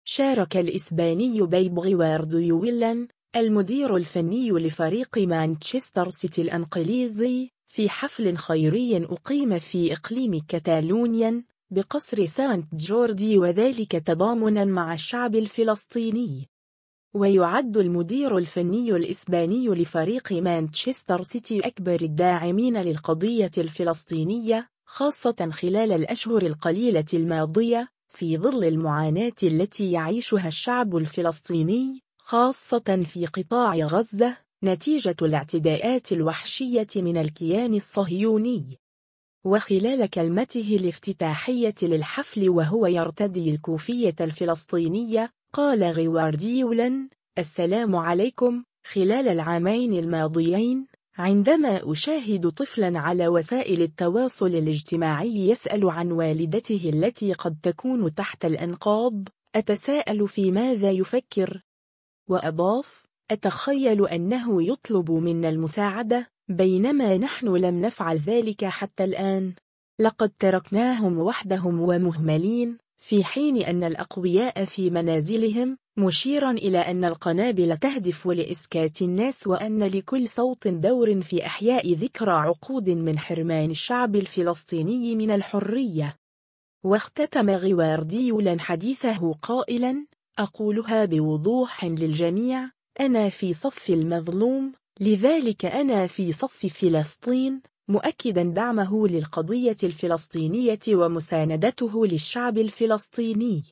شارك الإسباني بيب غوارديولا، المدير الفني لفريق مانشستر سيتي الإنقليزي، في حفل خيري أقيم في إقليم كتالونيا، بقصر سانت جوردي و ذلك تضامنًا مع الشعب الفلسطيني.